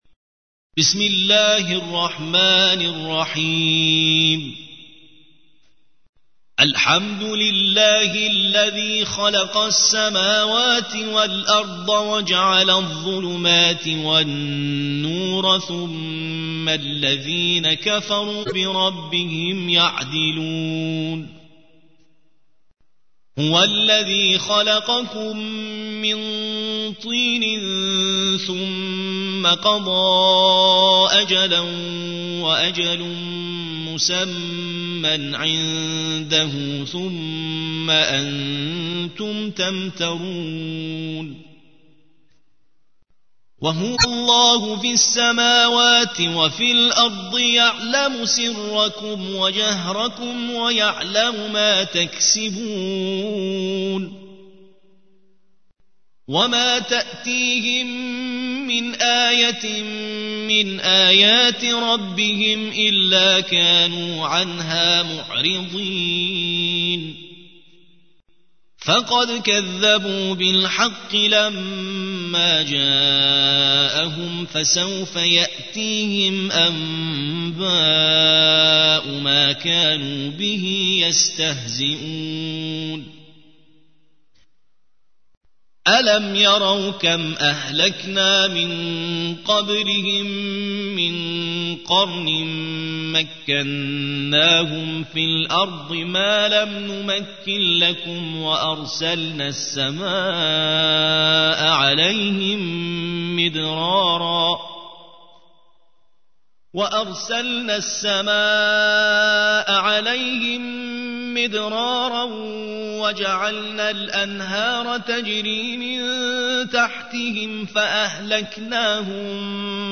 6. سورة الأنعام / القارئ